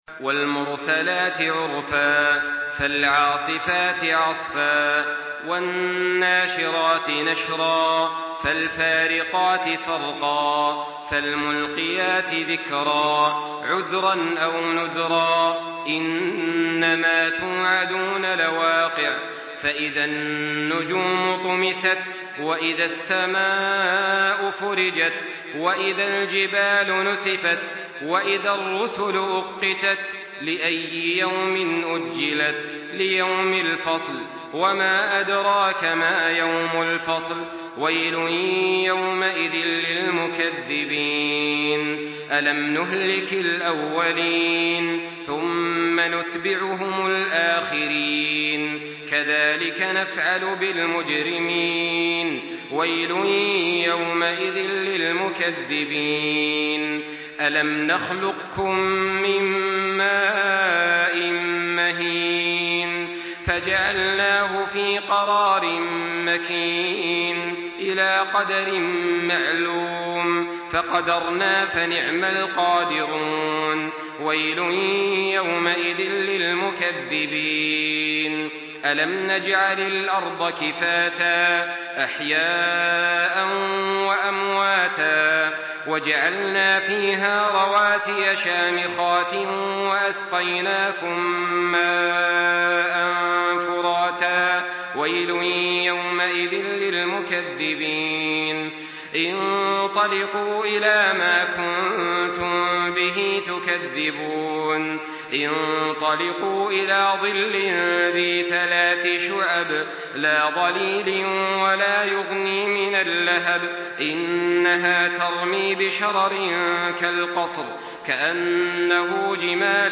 تحميل سورة المرسلات بصوت تراويح الحرم المكي 1424
مرتل